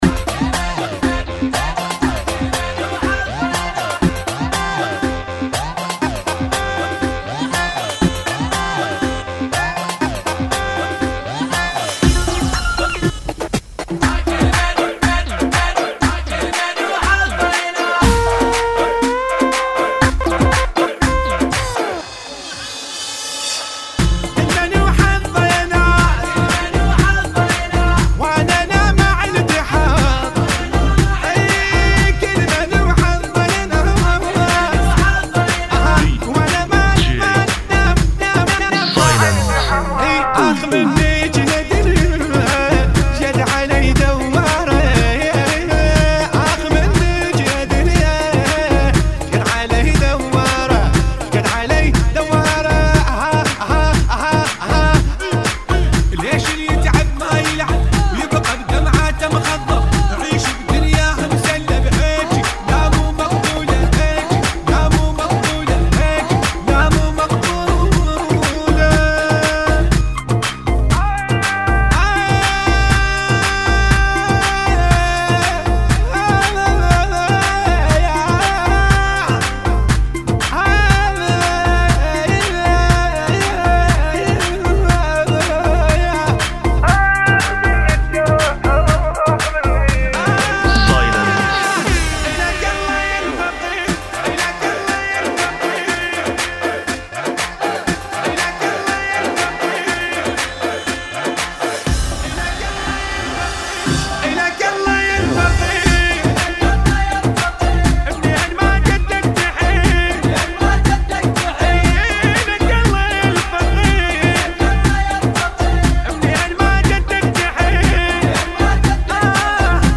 Rmx